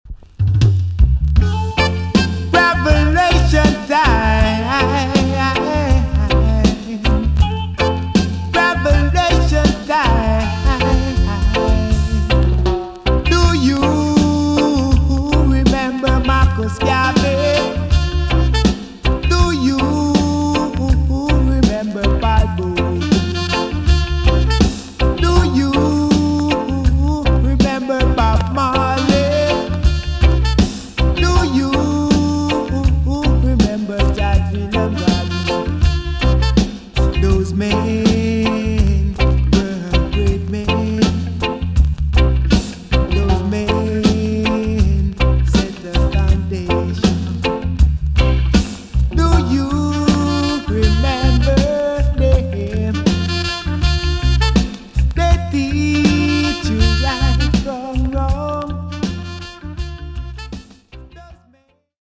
TOP >80'S 90'S DANCEHALL
EX- 音はキレイです。
1984 , NICE EARLY DANCEHALL TUNE!!